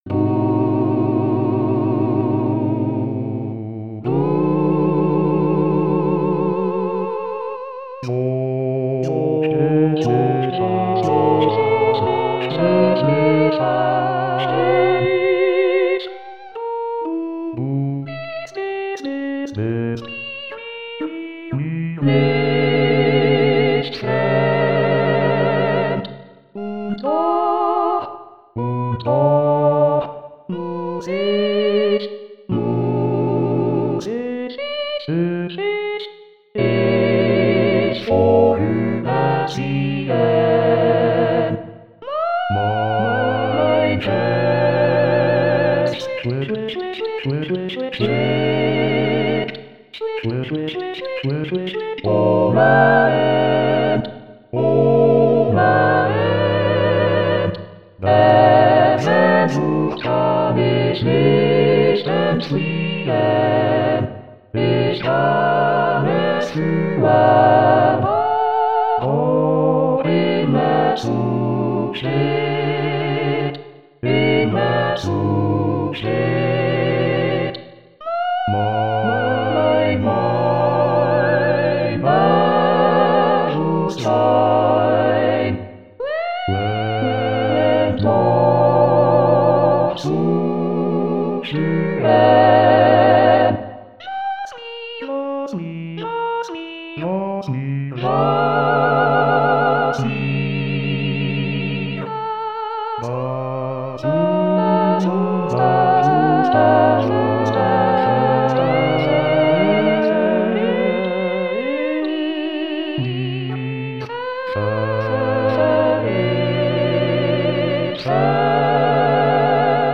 mp3-Aufnahme: Wiedergabe mit Gesang